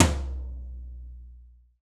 TOM TOM180TR.wav